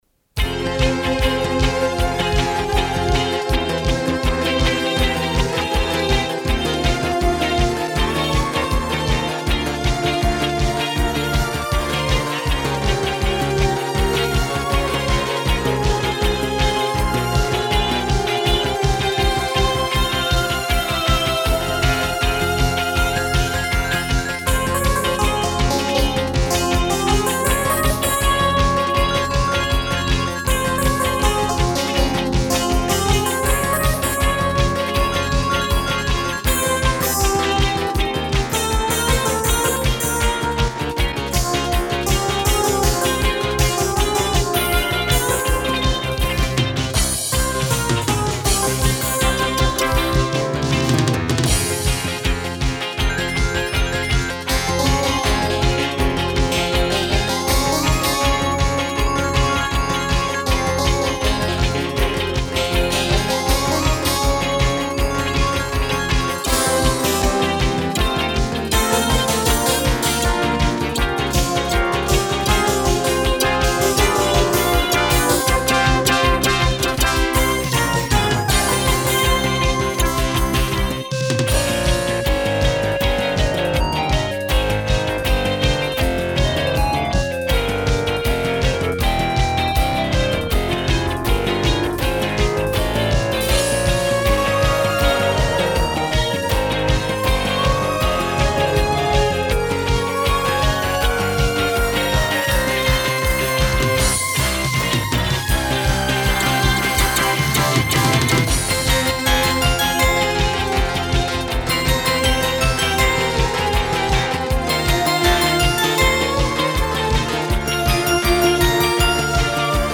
1989 г. моно. оцифрована в прошлом году.